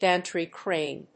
音節gántry cràne